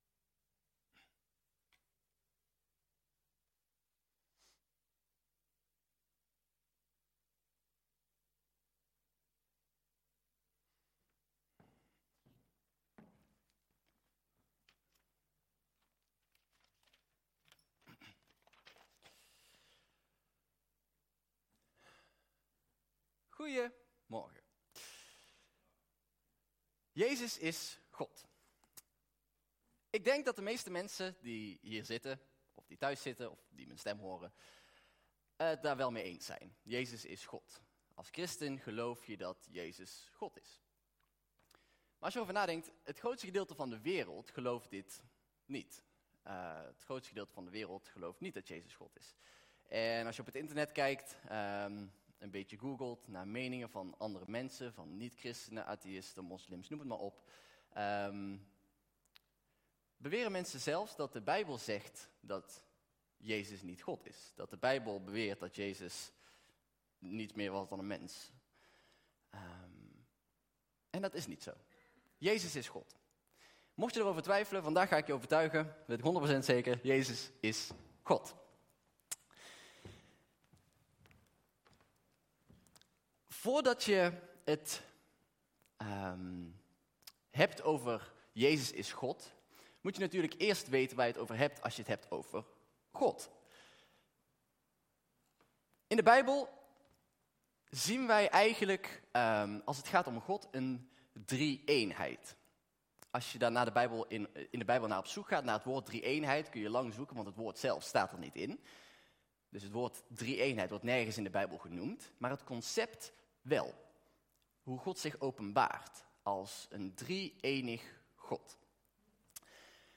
Toespraak 10 januari: Jezus, werkelijk God - De Bron Eindhoven